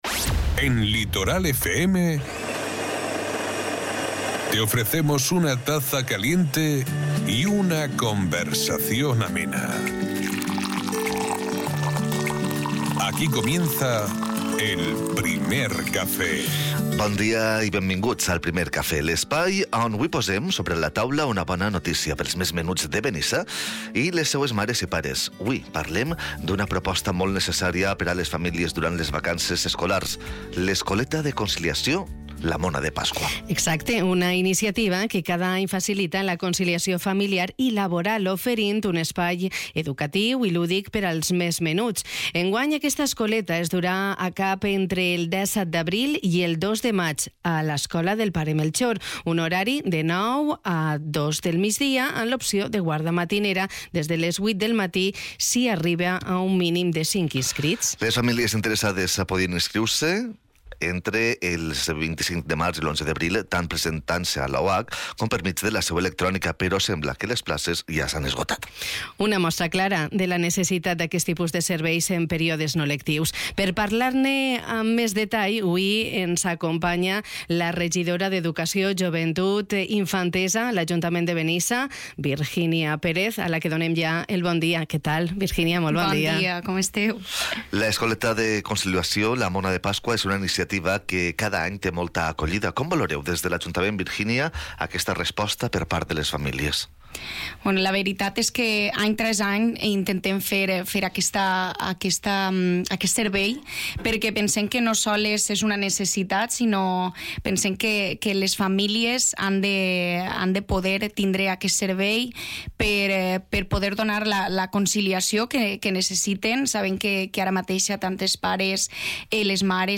Un servicio del que nos ha hablado la concejala de Educación Virginia Pérez, con la que nos hemos tomado nuestro Primer Café de hoy.